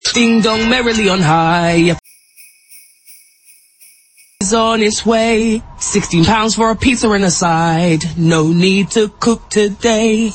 Which (jingle-belled-out) pizza brand does this Christmas ad belong to?
christmas-quiz-PJ-2025-w-bells.mp3